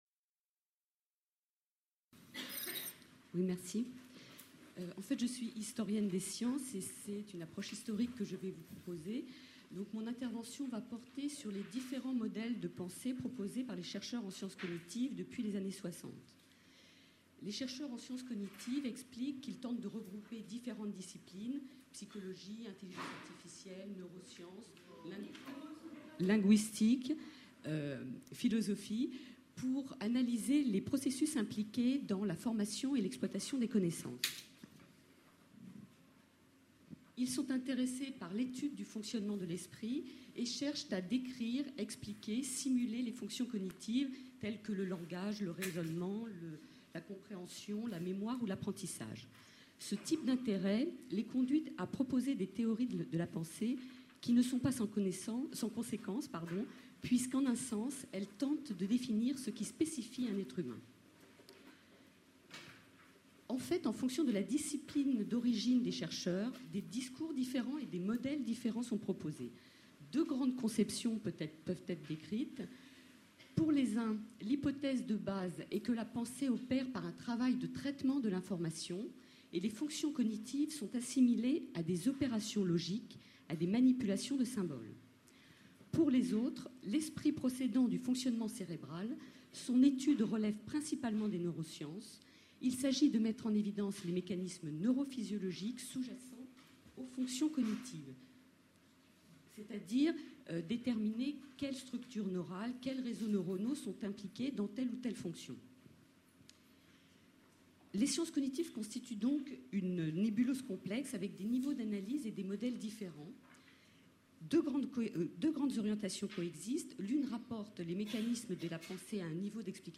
Colloque La représentation du vivant : du cerveau au comportement Session La représentation du cerveau par les neurosciences